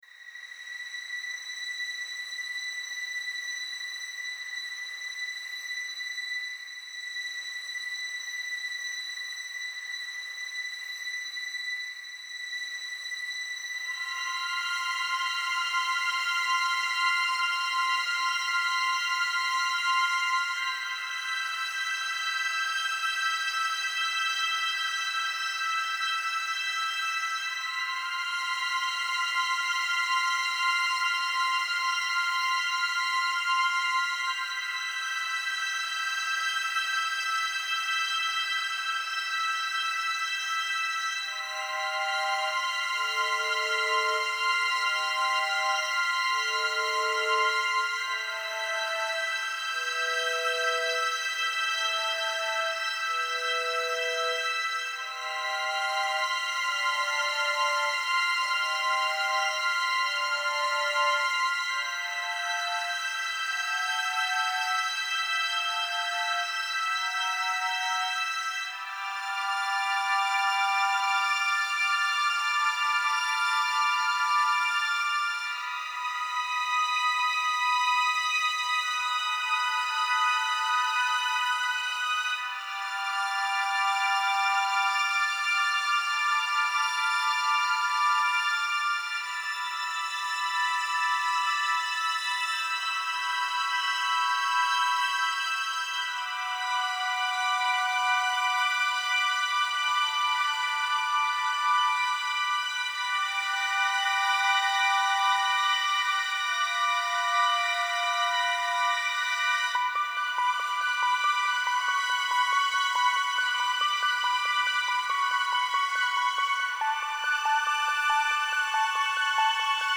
Style Style Orchestral, Soundtrack
Mood Mood Dark, Driving, Epic +2 more
Featured Featured Brass, Choir, Drums +3 more
BPM BPM 70